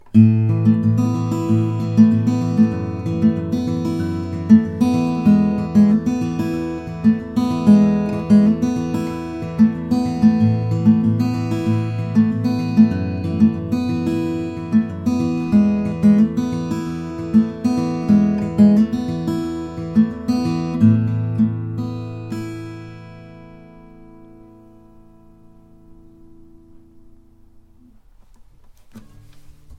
He made under fixed conditions samples of each guitar.
Rythm 4